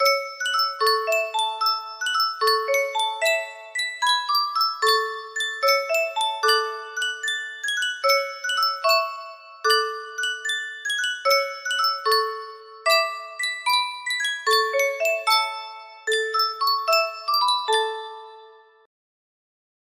Sankyo Music Box - Silent Night H music box melody
Full range 60